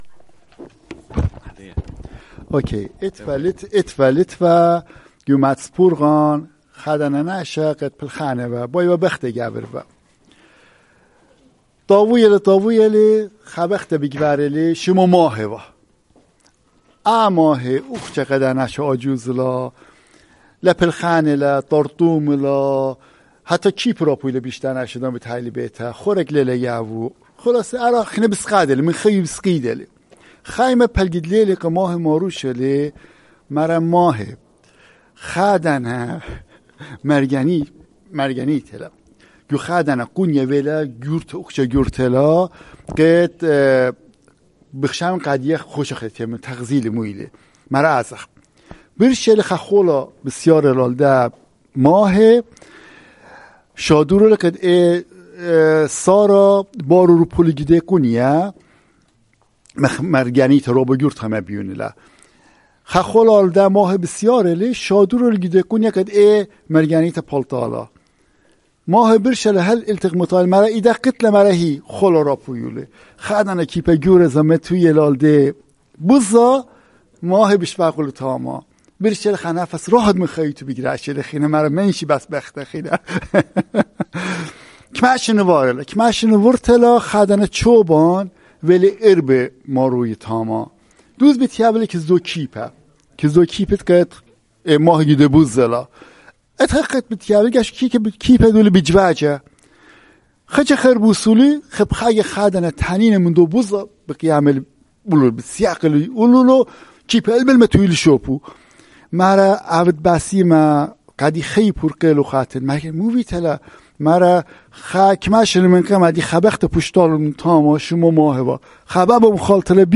Urmi, Christian: A Dragon in the Well